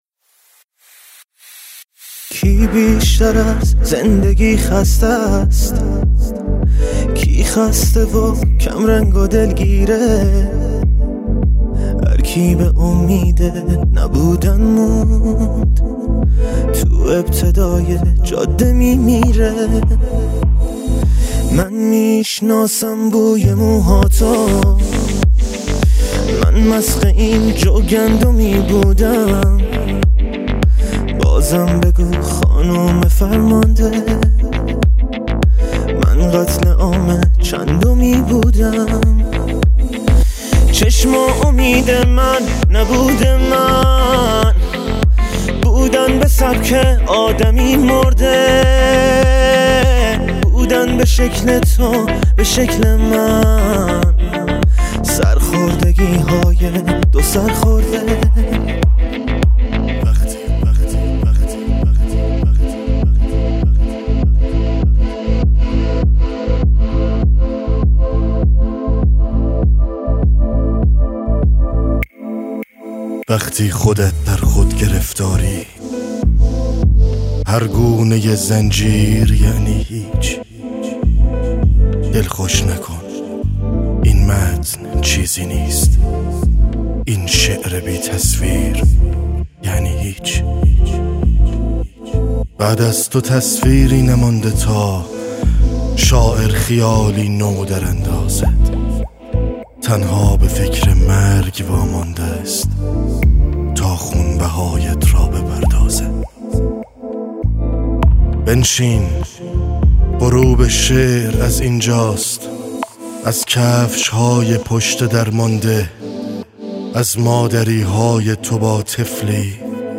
دانلود دکلمه نانحس با صدای علیرضا آذر